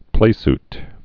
(plāst)